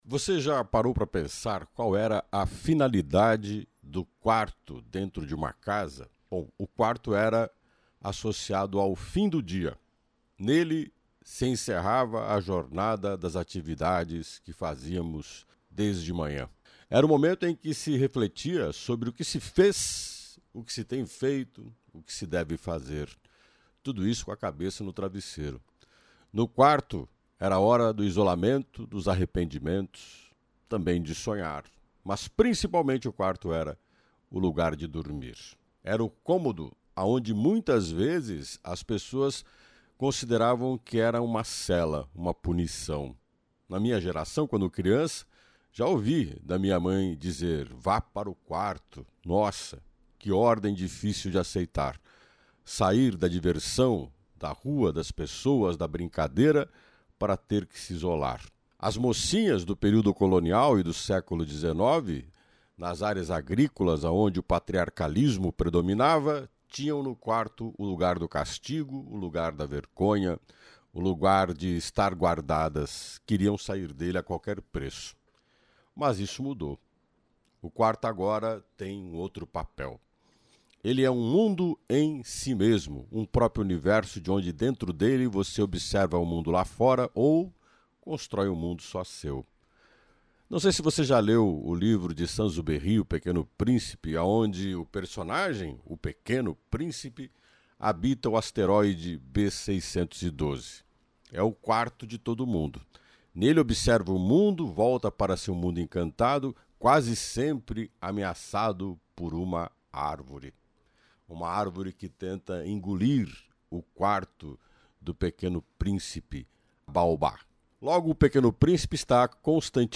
Opinião